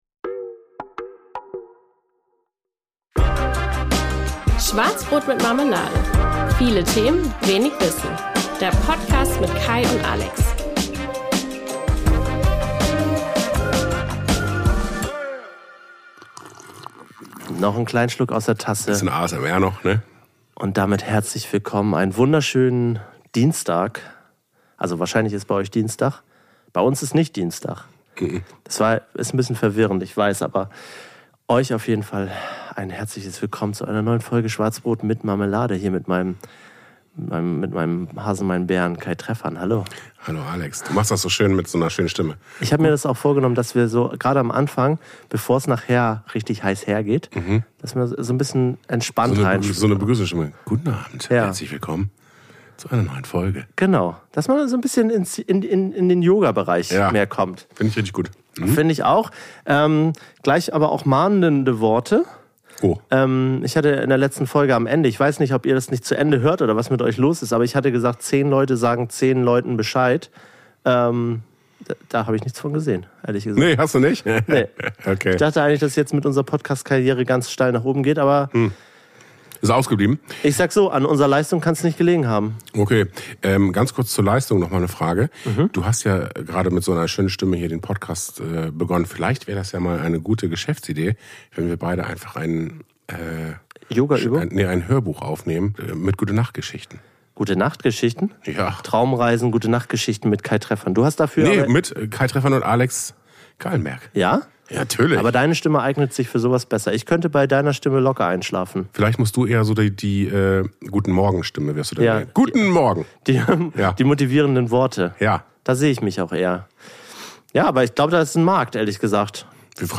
Feinstes ASMR, wie ihr es kennt und liebt. Weil Geld zwar nicht alles ist, aber eben doch ziemlich viel, haben wir eine neue Geschäftsidee am Start: Traumreisen und Gute-Nacht-Geschichten!